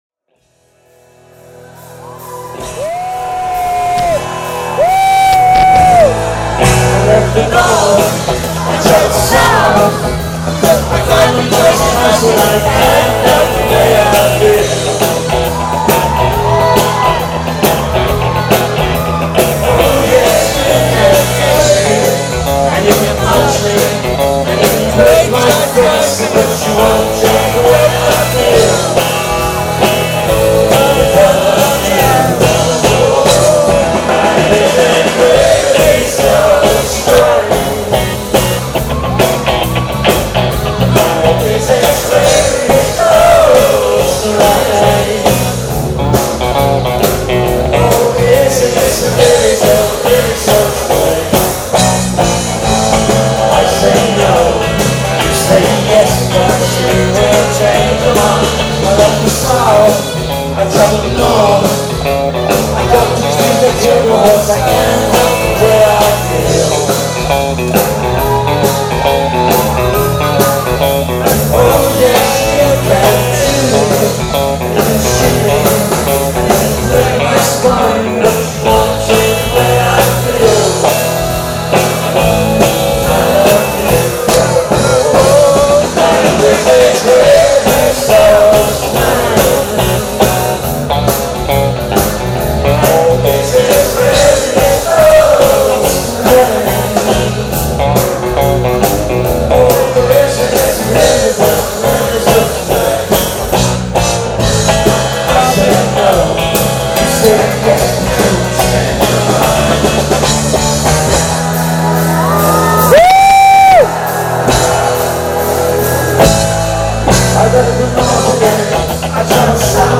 この時はさ、我慢したんだよね。歌うのを。
ひどい音源だけどね。